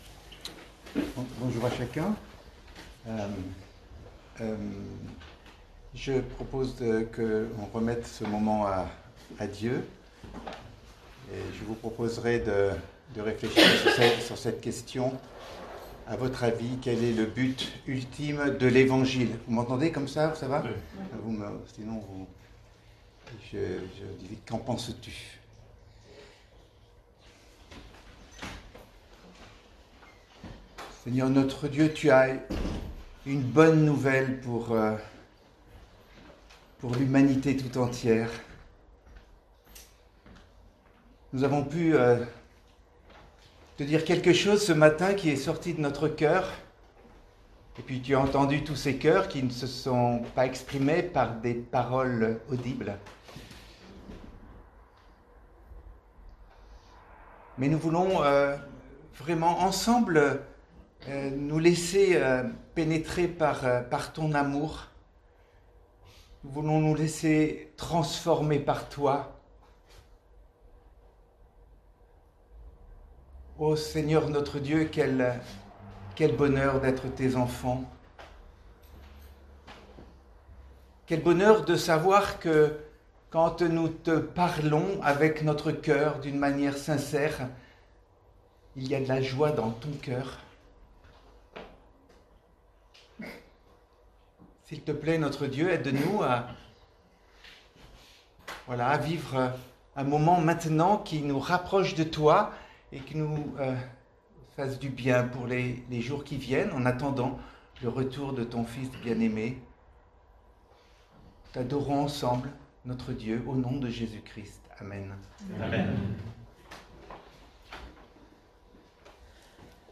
Orateurs